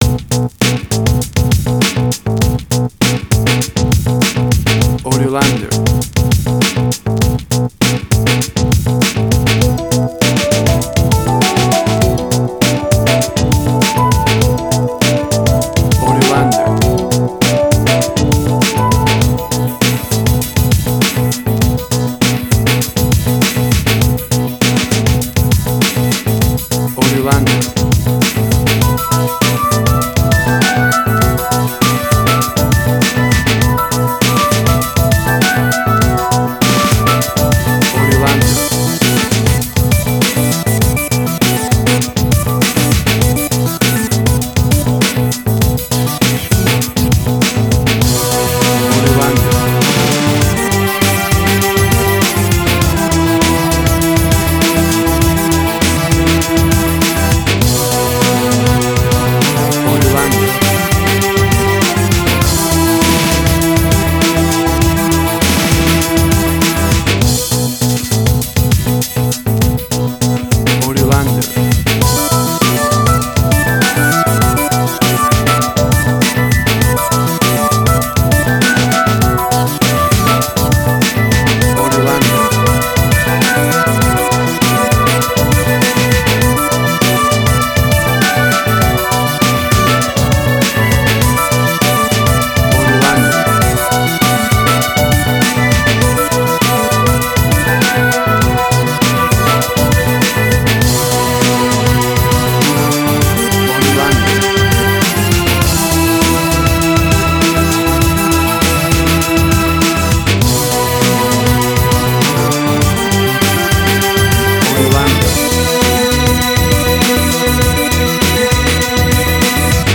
Suspense, Drama, Quirky, Emotional.
Tempo (BPM): 100